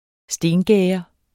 Udtale [ ˈsdeːn-ˈgεːʌ ]